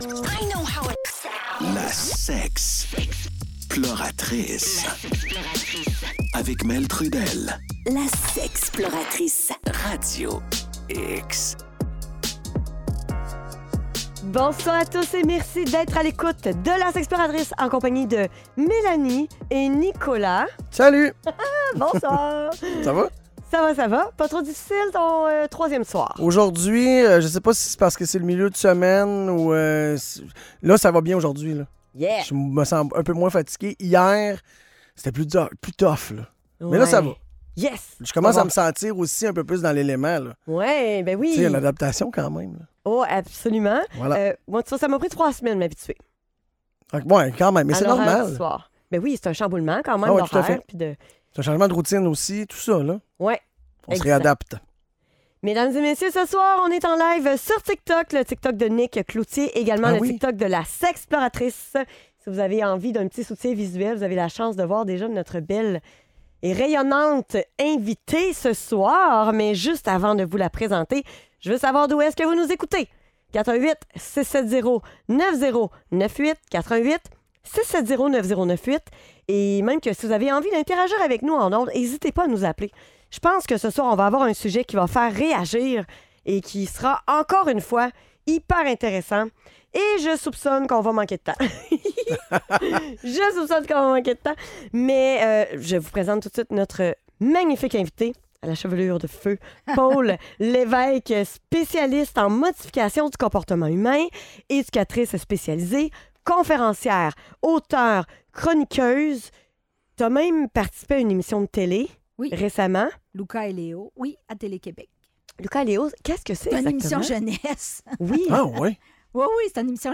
en studio